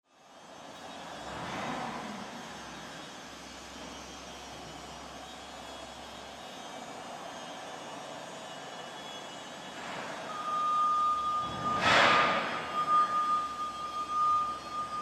Warehouse 01
Background Sound Effects
warehouse_01-1-sample.mp3